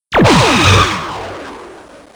plasma.wav